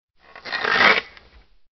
tautBow.ogg